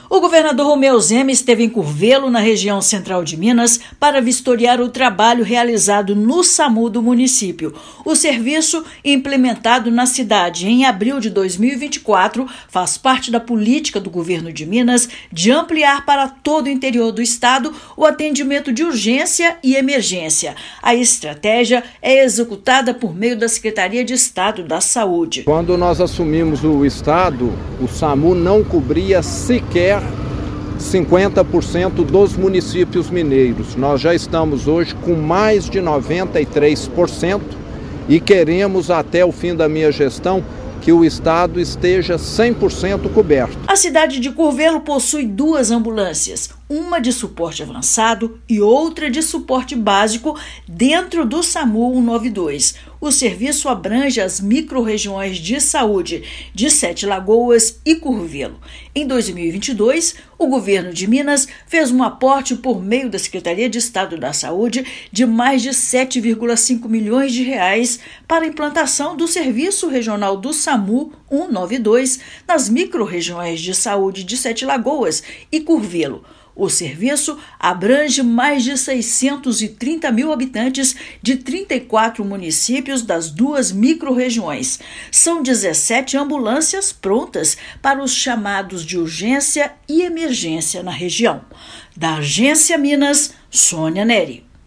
Governador vistoriou o Serviço de Atendimento Móvel de Urgência do município, que proporciona a diminuição do tempo de chegada das ambulâncias nas ocorrências, salvando muitas vidas. Ouça matéria de rádio.